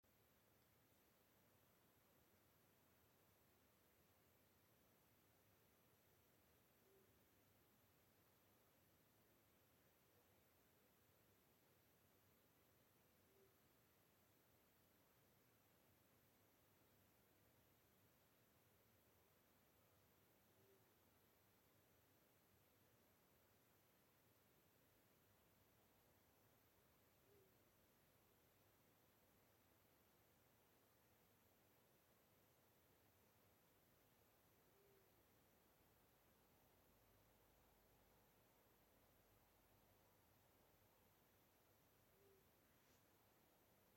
Long-eared Owl, Asio otus
StatusSinging male in breeding season
Notes/Vienmuļa, klusa, vienzilbīga ūjināšana. Punkts kartē vietā, kur balss sadzirdēta/ierakstīta.